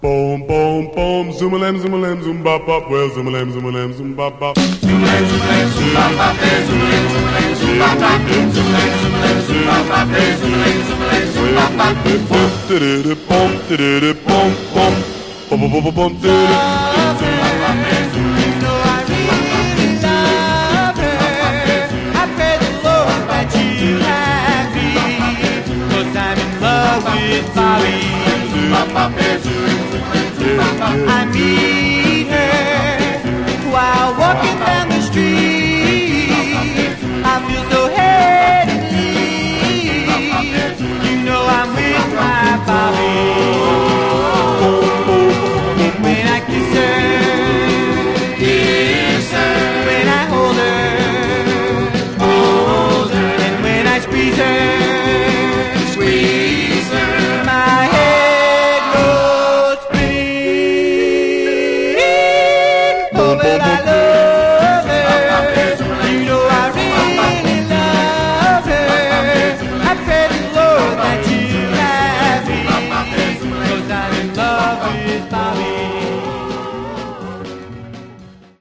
Genre: Vocal Groups (Doo-Wop)